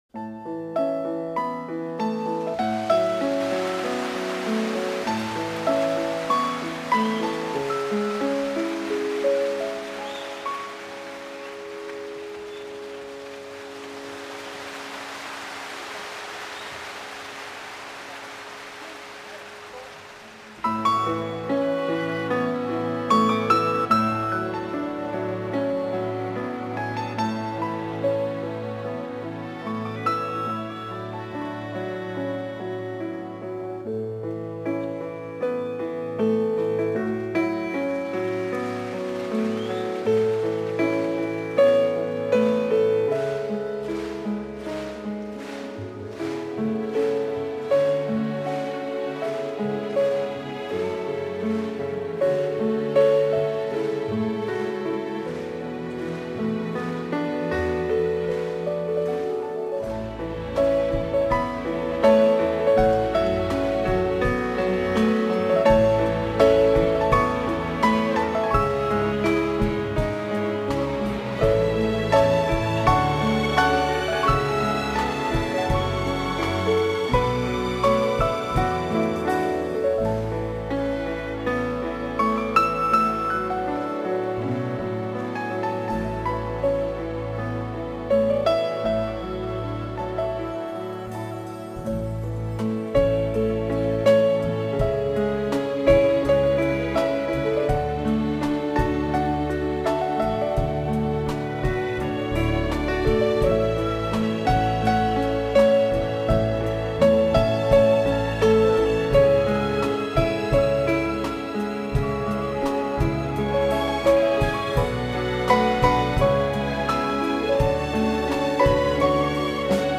大型音乐会
钢琴演奏